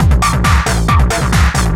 DS 136-BPM A3.wav